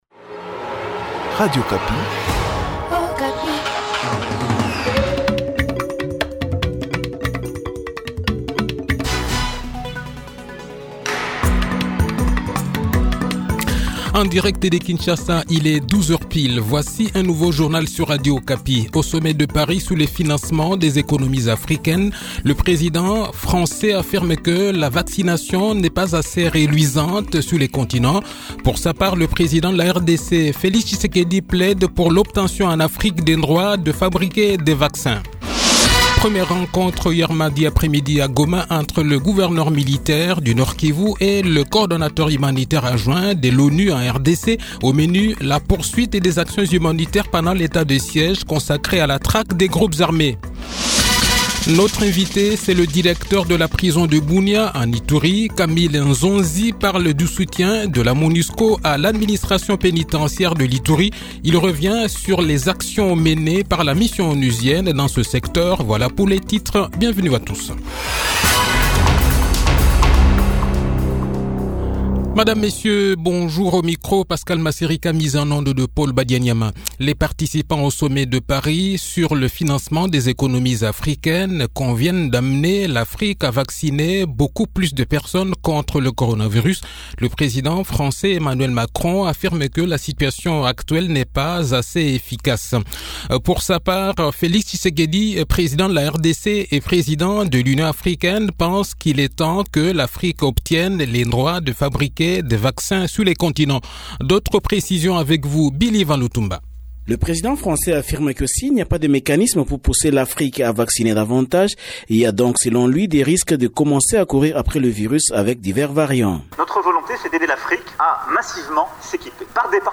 Le journal de 12 h, 19 Mais 2021